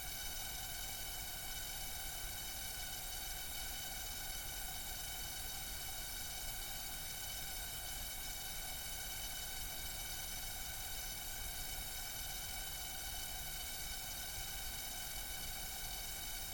Studio noise/Noise floor issue
I took two cables from two outputs and looped them into my preamps and recorded the result. I boosted the volume to hear what was going on and to my suprise, it contained audible pitchs.
I've attached a sample of the boosted noise and a freq analysis. Just looking at the harmonics I can make out an F#(5oct), but there is obviously more going on futher up the graph.
Also, any ideas what might be causing all the bass noise?